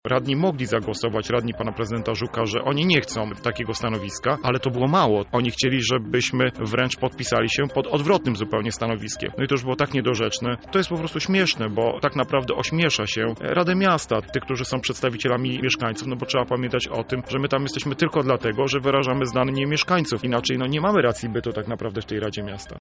Sytuację skomentował miejski radny dr Robert Derewenda w Porannej Rozmowie Radia Centrum: